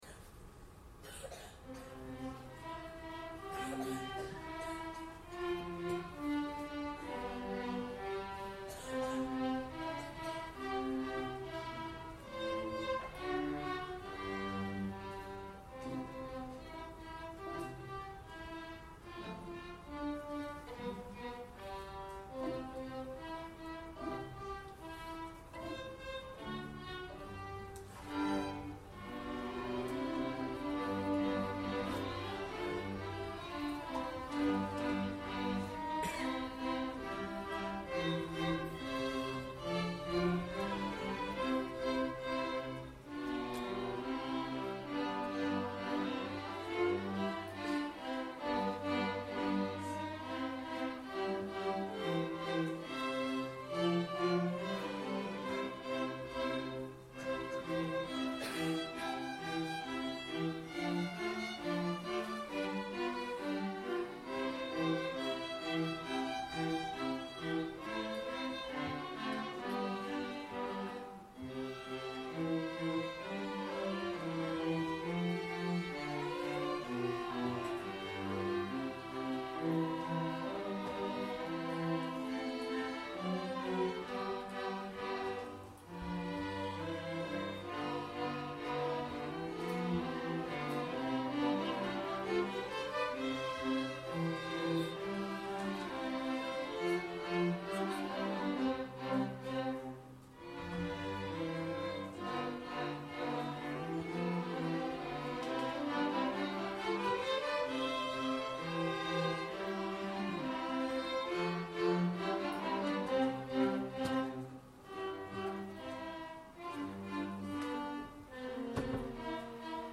String Ensemble first piece
Summer Concert 2014